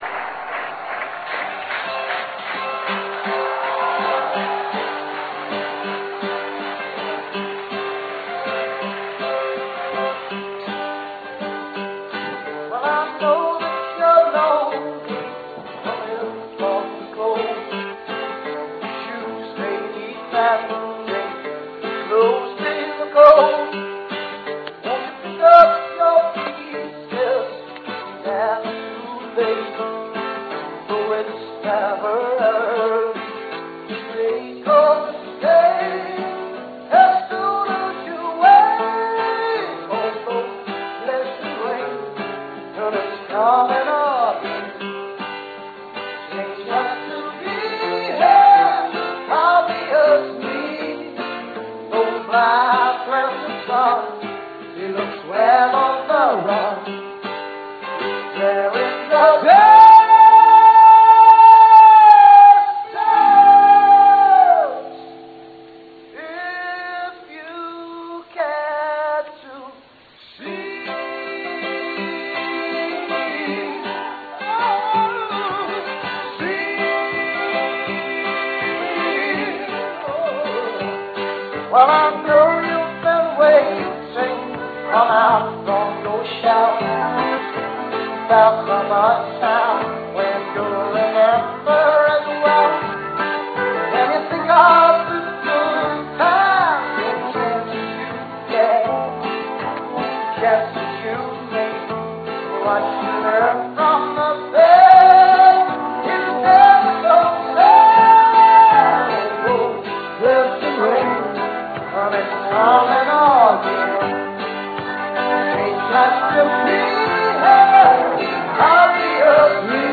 LIVE!
Unplugged!